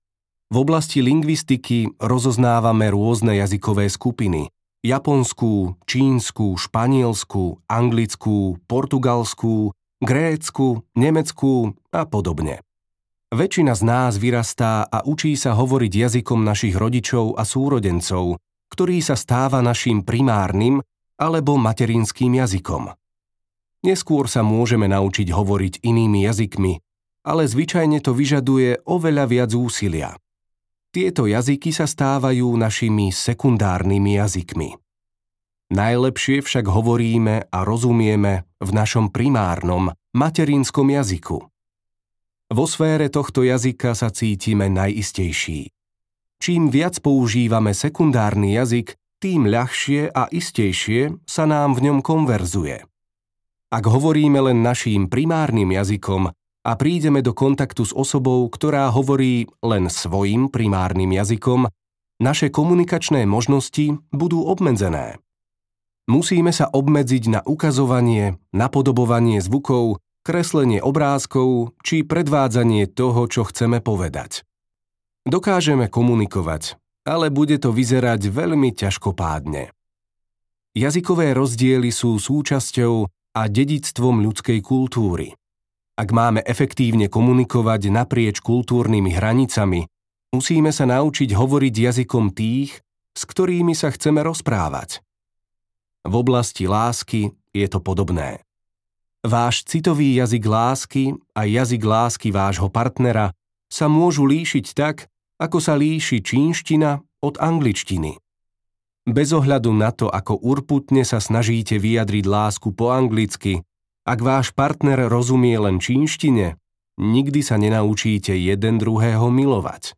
audiokniha